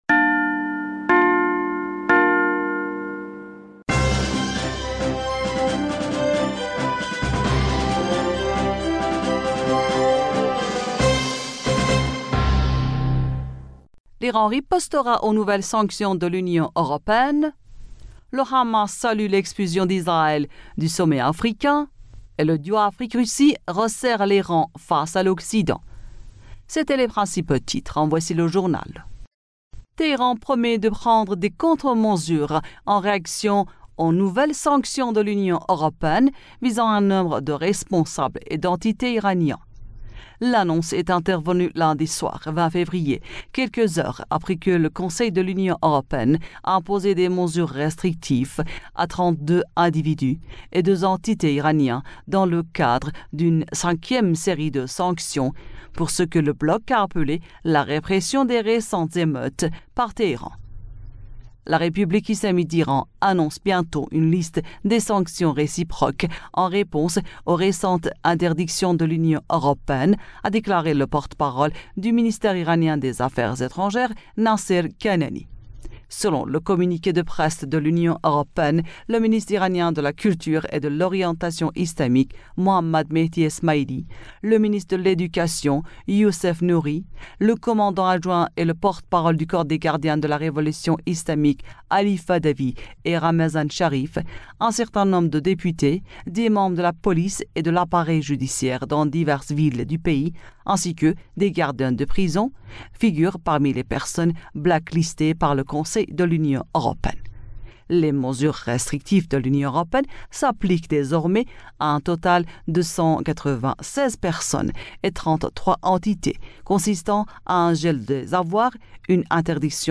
Bulletin d'information du 21 Février